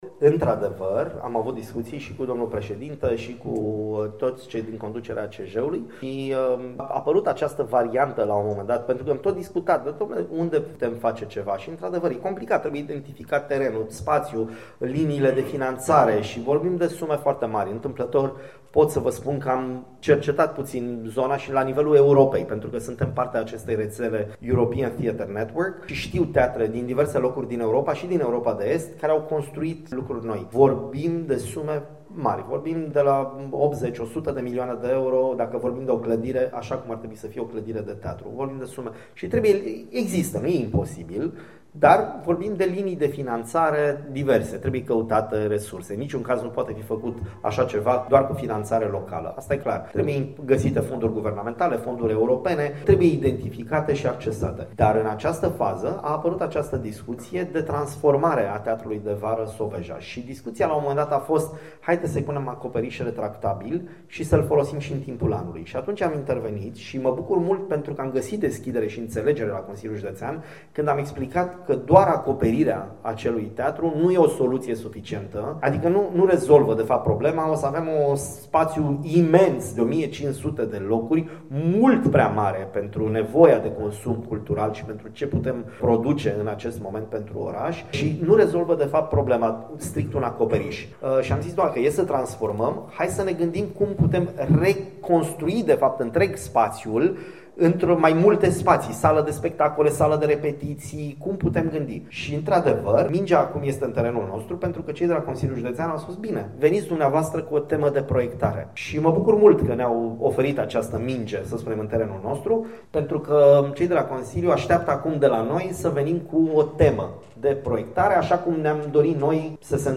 Despre acest viitor proiect ne-a oferit detalii în cadrul conferinței de presă în care a anunțat bilanțul ediției a IV-a a Stagiunii Estivale a Artelor Spectacolului la Constanța.